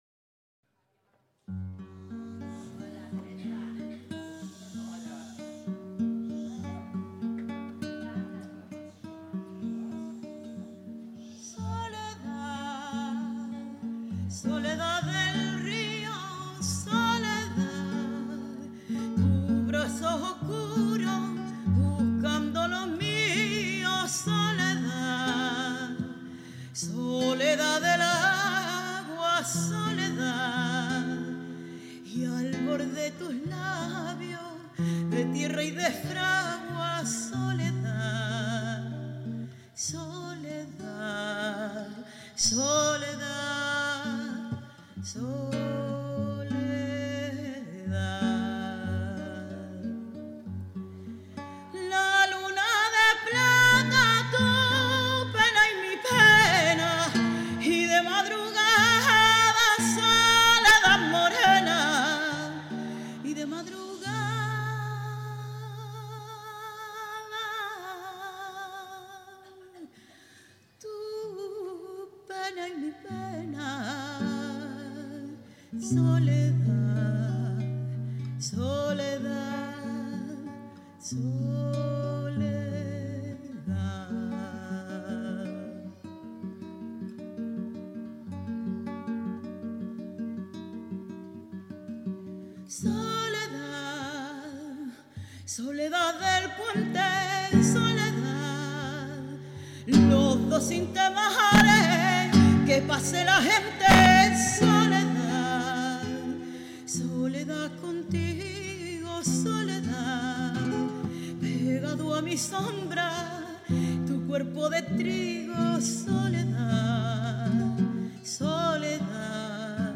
Guitarra
registro vocal de contralto